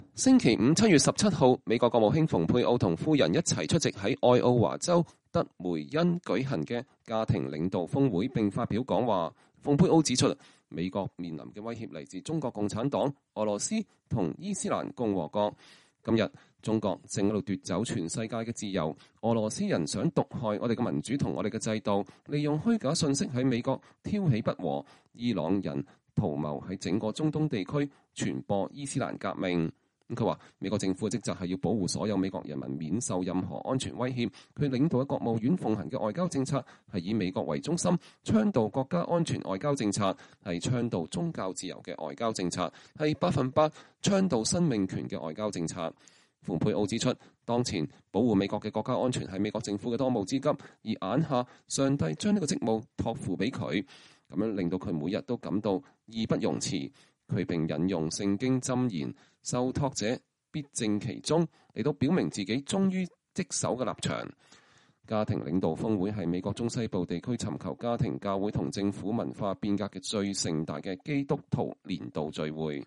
星期五(7月17日)，美國國務卿蓬佩奧與夫人一同出席在愛奧華州得梅因舉行的“家庭領導峰會”，並發表講話。